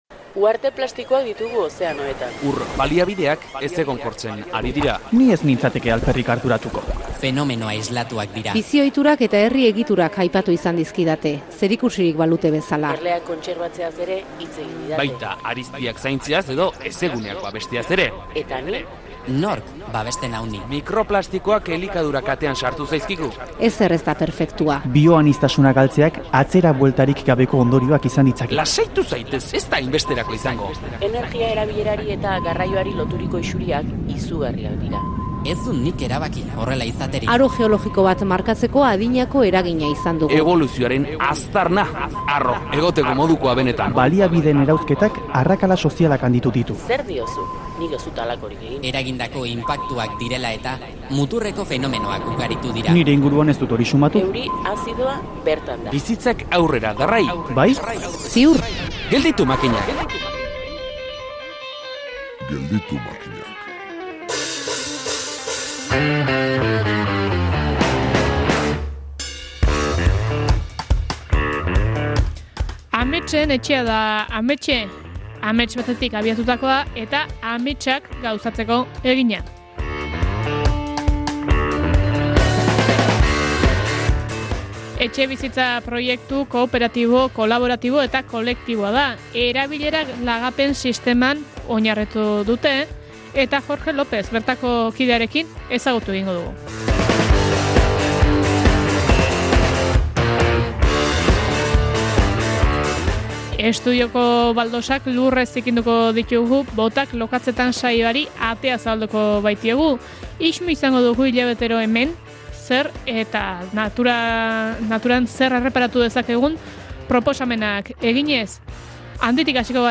Elkarrizketak adituei eta baita Euskal Herrian martxan jarritako eredu jasangarriei.